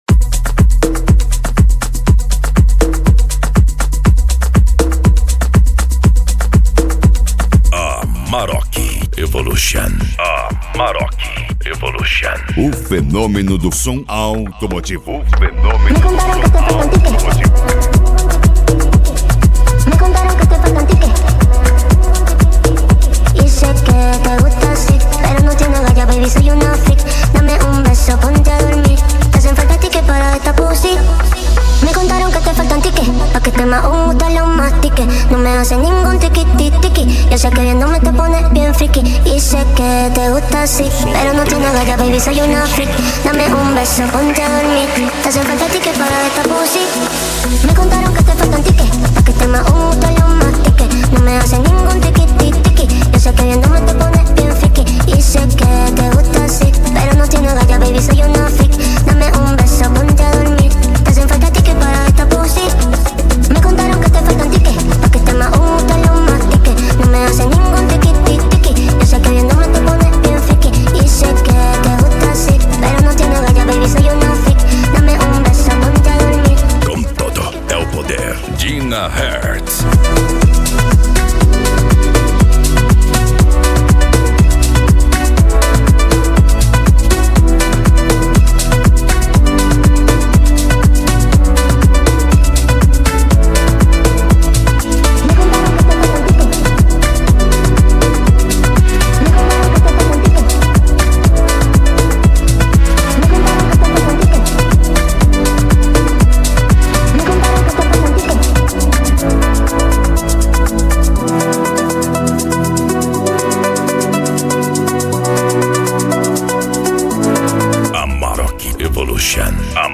Funk
Remix